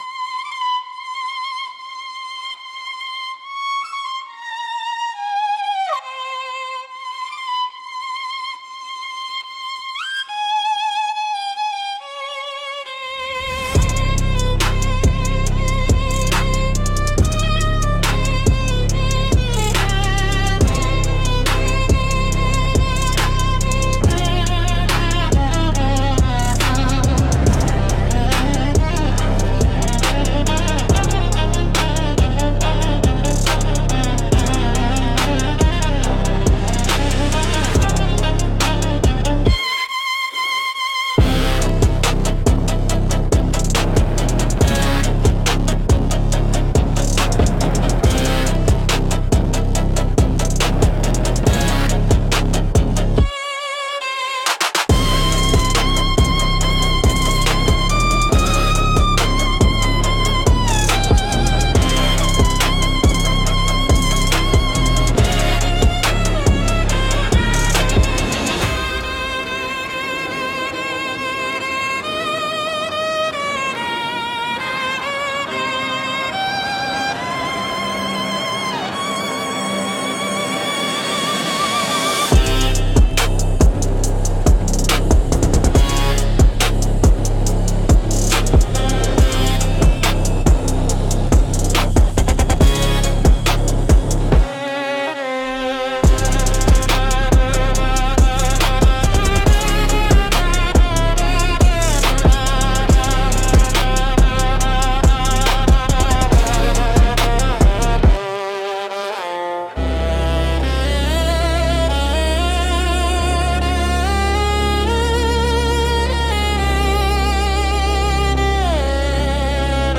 Instrumental - What If I Say Yes_ 2.32